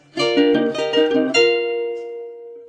private_message.ogg